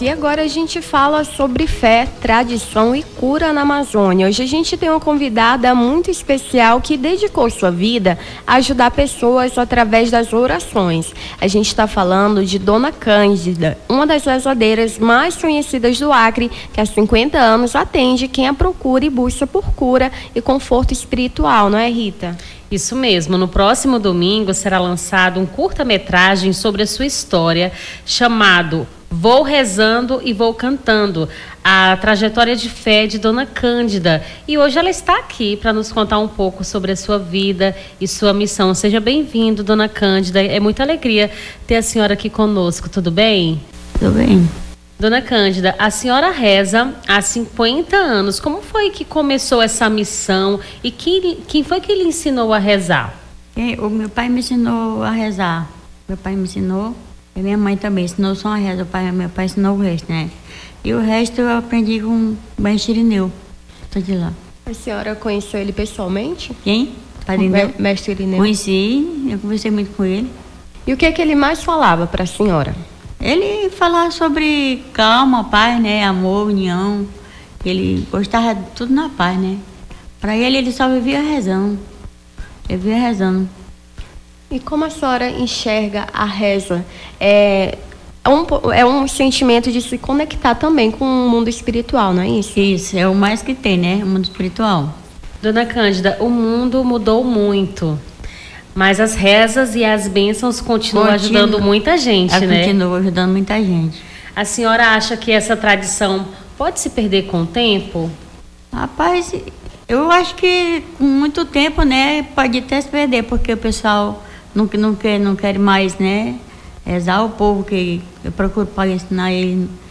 Nome do Artista - CENSURA - ENTREVISTA CURTA-METRAGEM REZADEIRA ACREANA (31-01-25).mp3